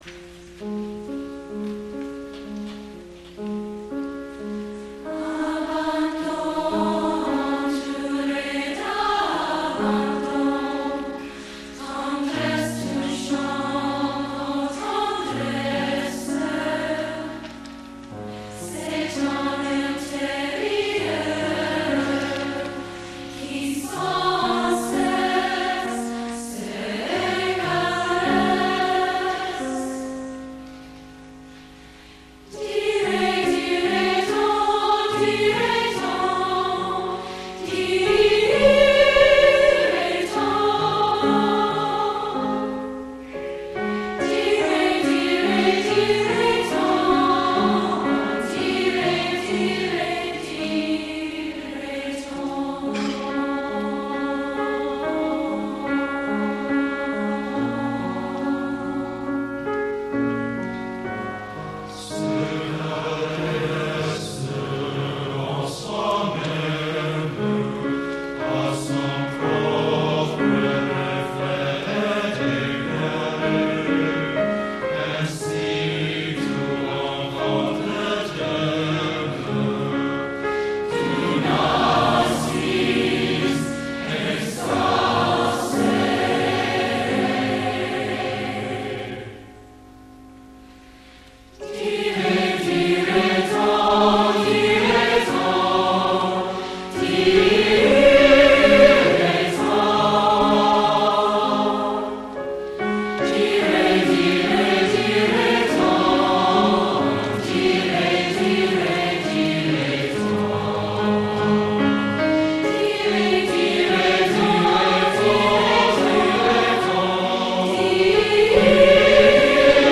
very slow choral version